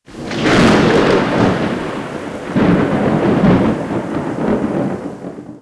Thunderstorm
Thunderstorm.wav